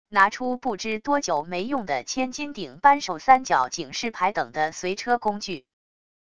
拿出不知多久没用的千斤顶扳手三角警示牌等的随车工具wav音频